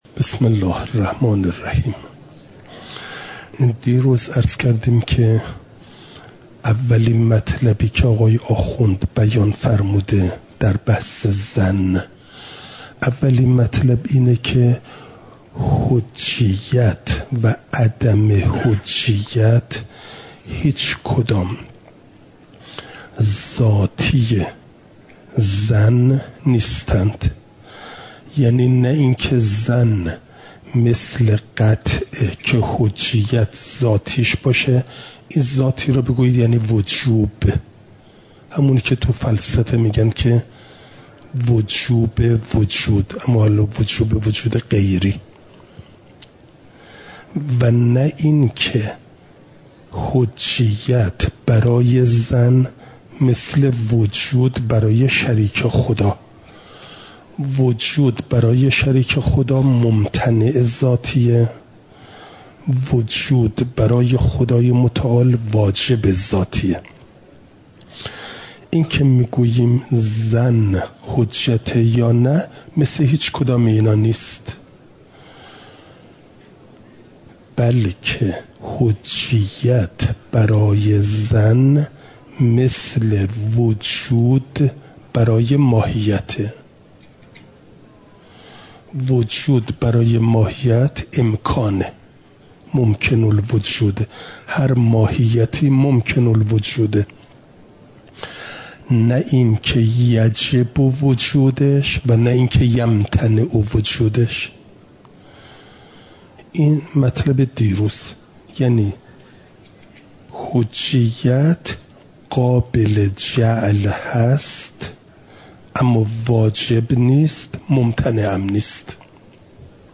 امارات؛ قطع و ظن (جلسه۵۱) – دروس استاد